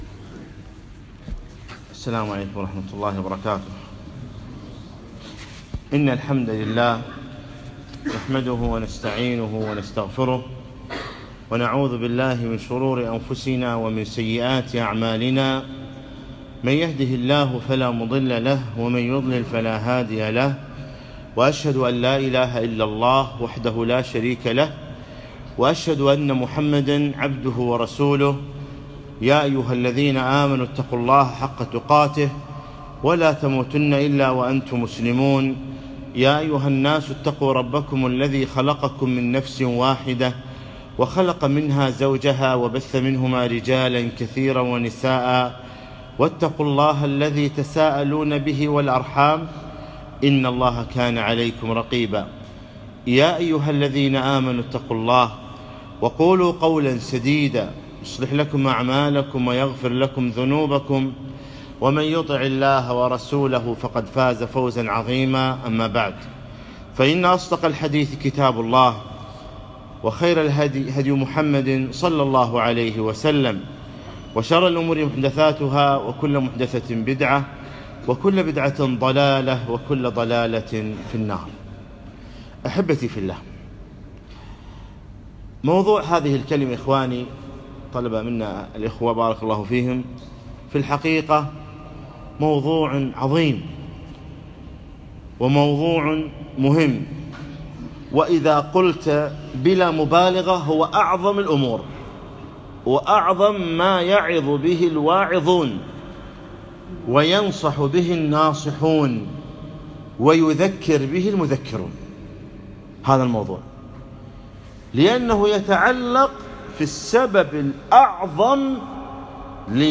يوم الأربعاء 28 جمادى الأخر 1437 الموافق 6 4 2016 في مسجد حجي سعد الصليبية